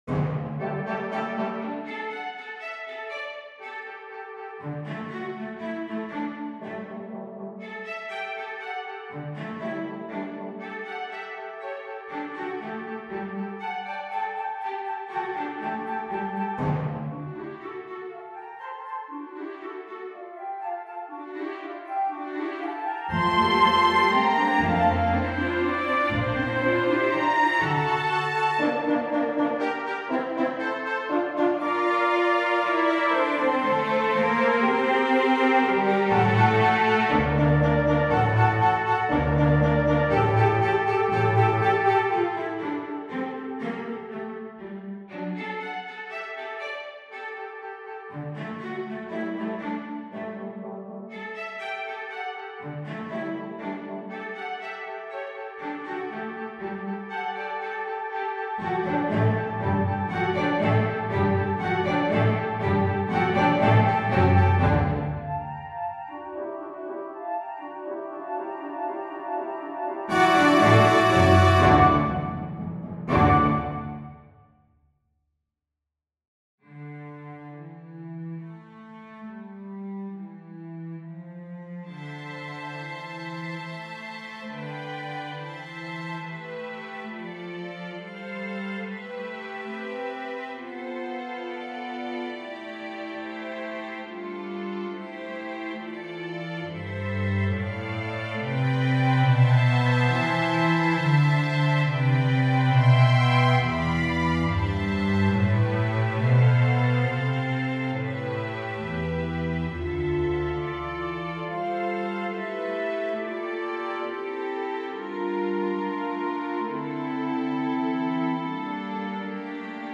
Information on composer Randall Shinn's Village Scenes for youth orchestra
Orchestra: 2.2.2.1, 2.2.1.0, timp, 1 perc, strings
Barn Dance Download the audio (This audio demo was made using instruments from the Vienna Symphonic Library.)